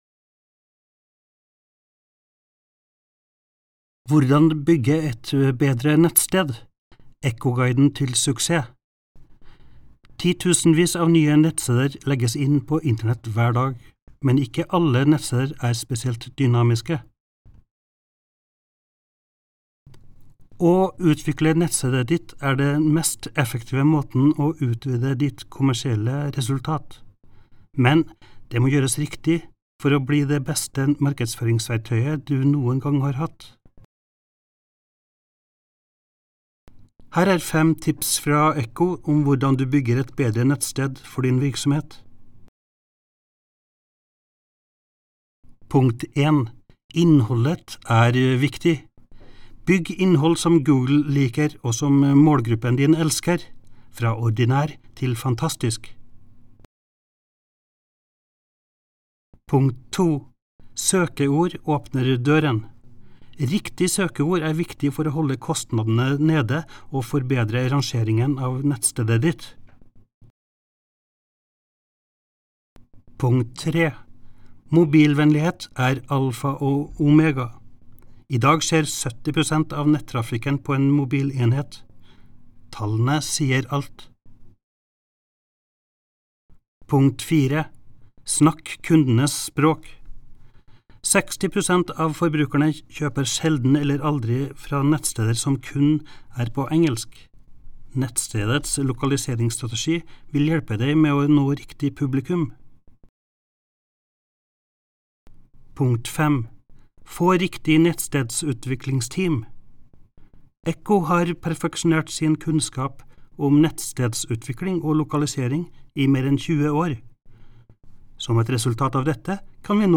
Norwegian voiceover talents - EQHO
Norwegian Male
NARRATION